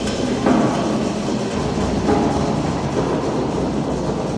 lower_hallway_amb.ogg